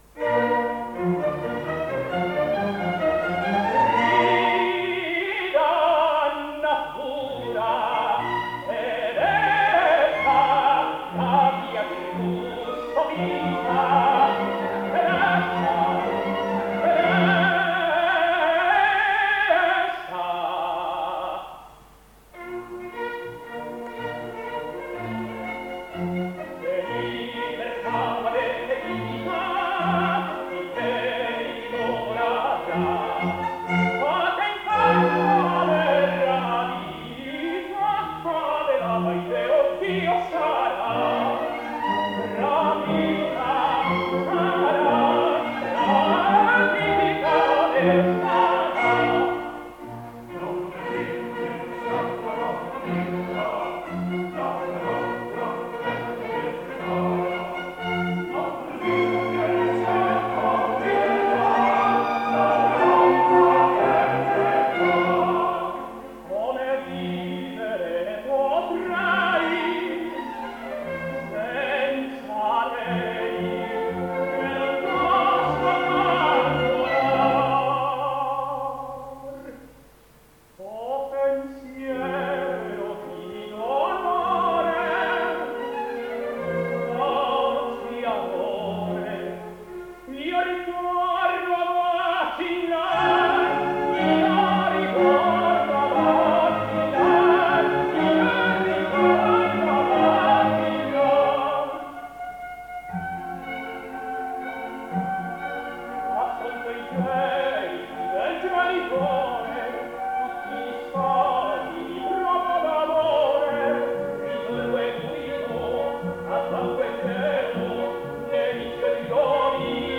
Ernesto Palacio singsAdelaide di Borgogna:
He was almost exclusively a belcanto specialist and sang Elvino, Ernesto, Nemorino, and above all Rossini: Almaviva and Lindoro of course, Don Ramiro (La Cenerentola), Argirio (Tancredi), Giannetto (La gazza ladra), Dorvil (La scala di seta), Edward Milford (La cambiale di matrimonio), Don Narciso (Il turco in Italia), Torvaldo (Torvaldo e Dorliska), Bertrando (L'inganno felice), Adelberto (Adelaide di Borgogna), Baldassare (Ciro in Babilonia), Paolo Erisso (Maometto secondo), Pirro (Ermione), Osiride (Mosè in Egitto).